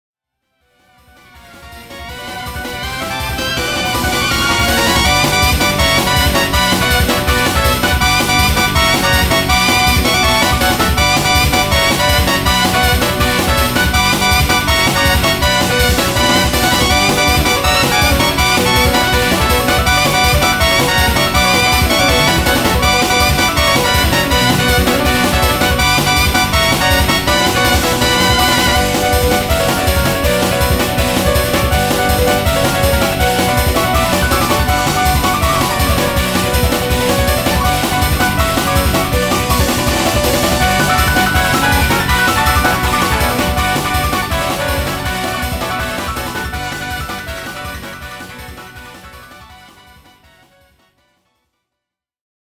東方風自作曲
EXボス曲として作った楽曲です。